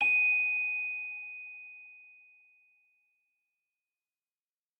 celesta1_14.ogg